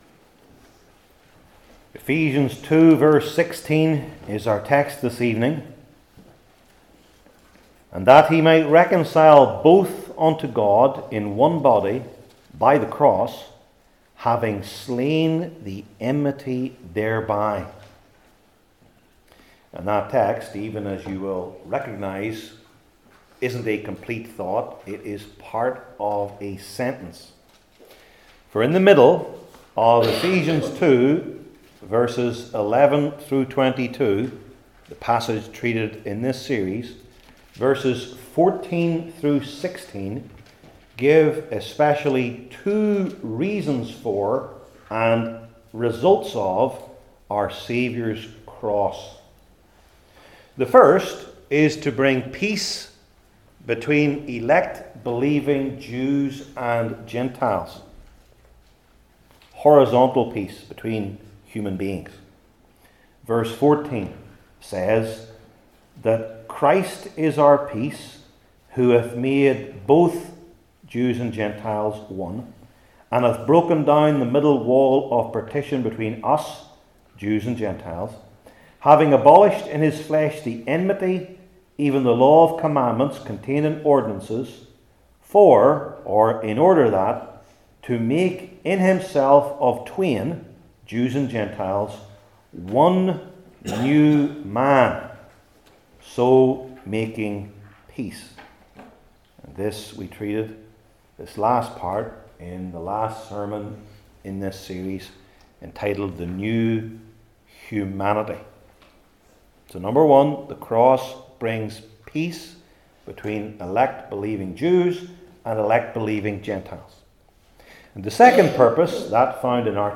Ephesians 2:16 Service Type: New Testament Sermon Series I. Its Meaning II.